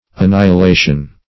Annihilation \An*ni`hi*la"tion\, n. [Cf. F. annihilation.]